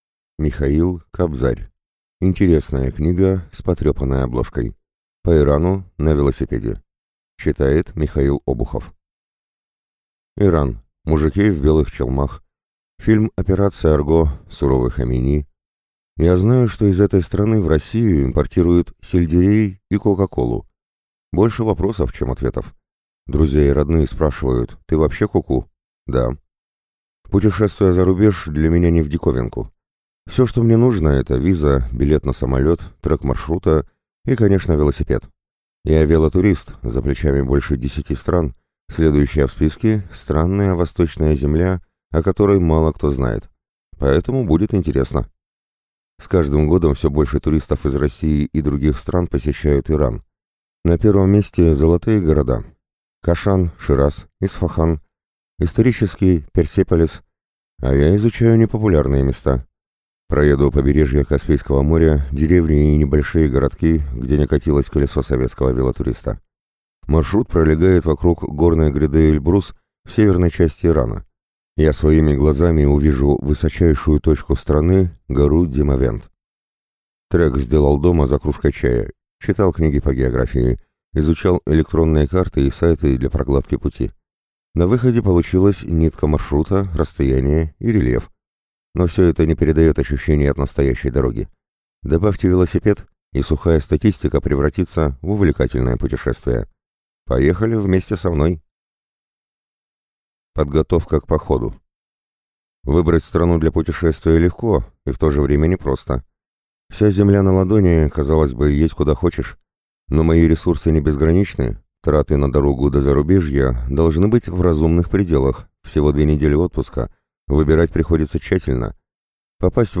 Аудиокнига Интересная книга с потрепанной обложкой. По Ирану на велосипеде | Библиотека аудиокниг